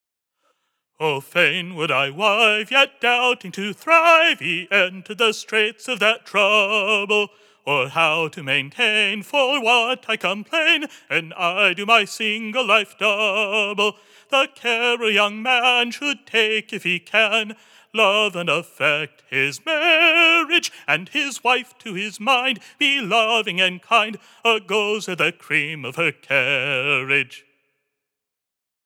4_handprint_ballad_m2.47b_leap.mp3 (1.1 MB)
Stanza 1, sung with leap in notes